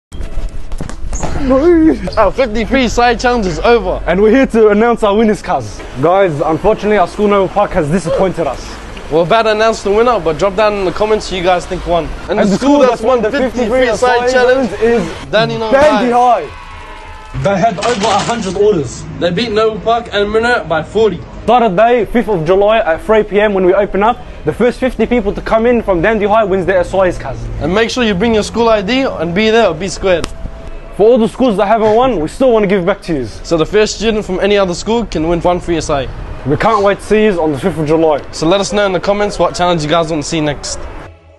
🥁 DRUM ROLL PLEASE… 🥁 sound effects free download